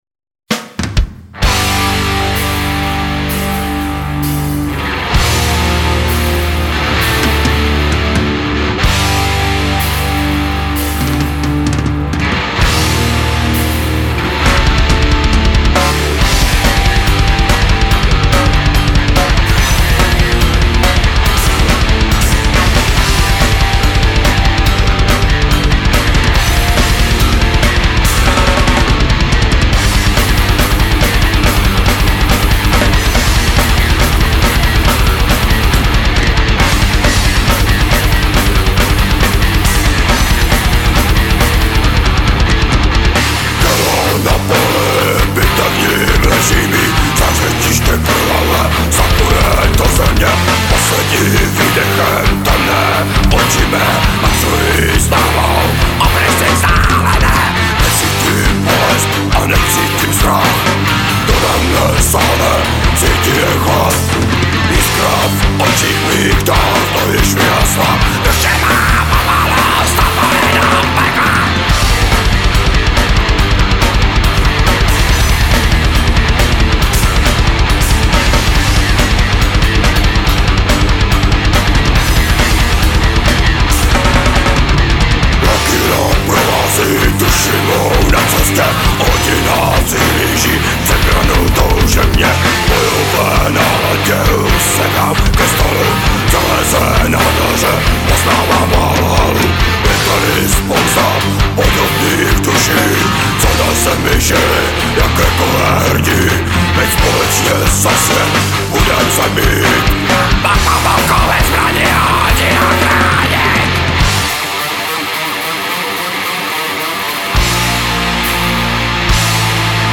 Bicí